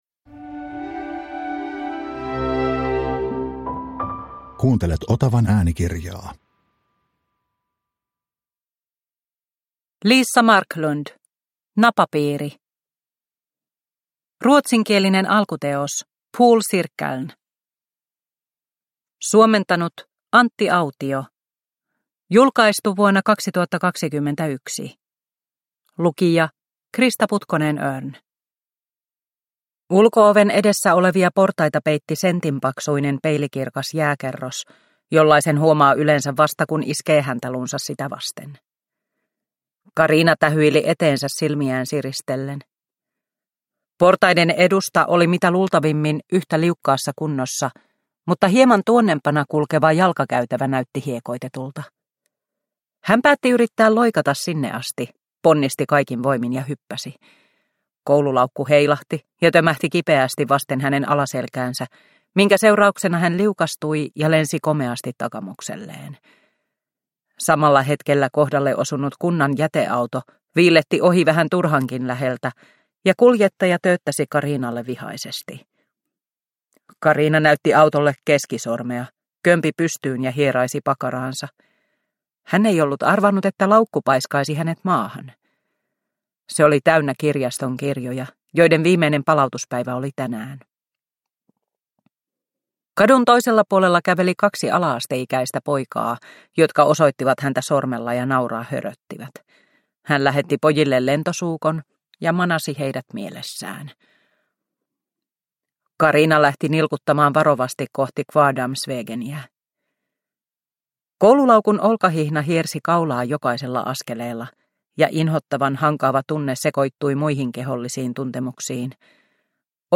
Napapiiri – Ljudbok – Laddas ner